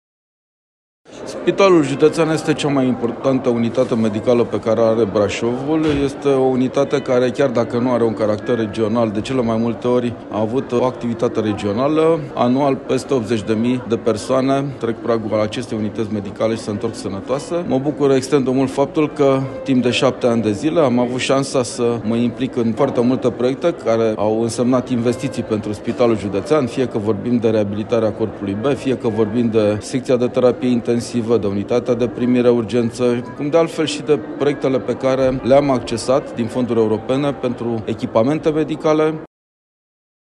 Cu acest prilej, reprezentanții unității medicale au organizat, astăzi, un eveniment aniversar la care participă foști și actuali angajați, dar și oficialități care s-au implicat, de-a lungul timpului, pentru dezvoltarea spitalului brașovean.
Ministrul Dezvoltării, Lucrărilor Publice și Administrației, Adrian Veștea: